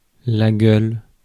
Ääntäminen
IPA : /ɡɒb/